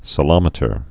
(sə-lŏmĭ-tər)